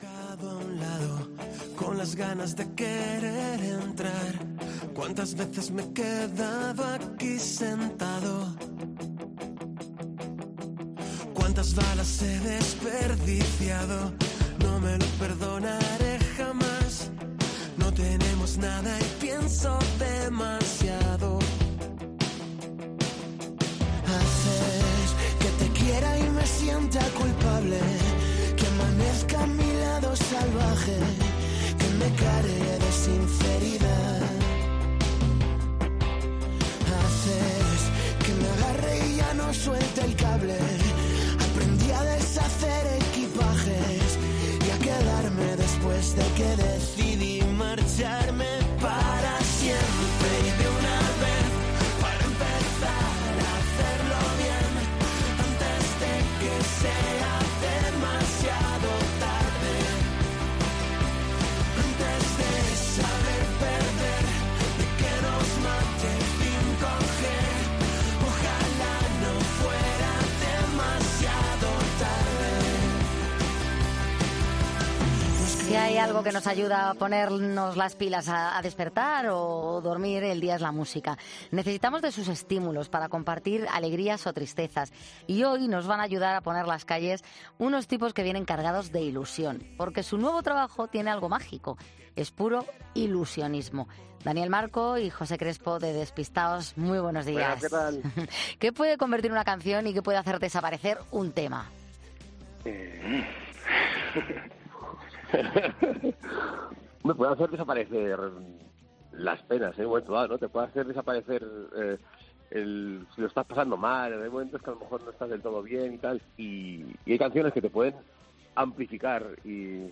El grupo ha acudido a la radio para presentar 'Ilusionismo' y dar el pistoletazo de salida a esta nueva apuesta musical donde mantienen su sello...